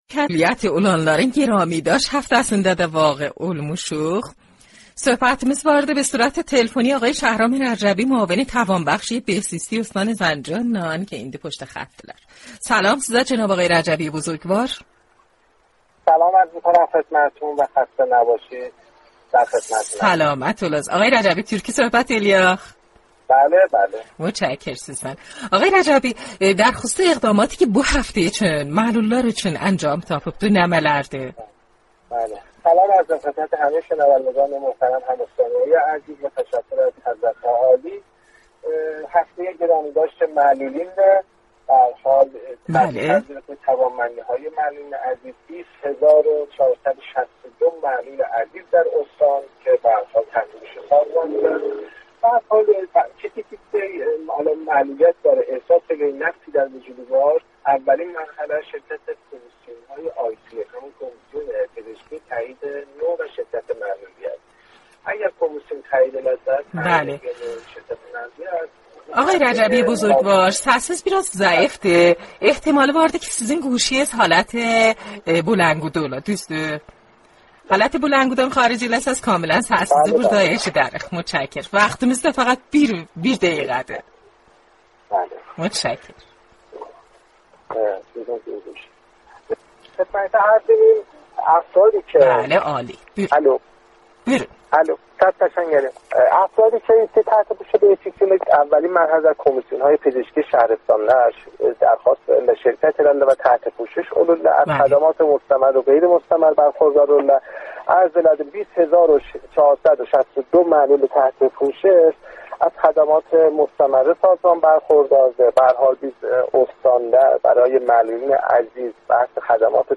باهم بشنویم| مصاحبه رادیویی معاون امور توانبخشی بهزیستی استان زنجان با برنامه یاشاییش از رادیو زنجان